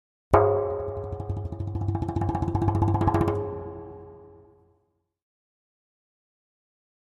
Drums Percussion Danger - Fast Drumming On A Thin Metal Percussion 2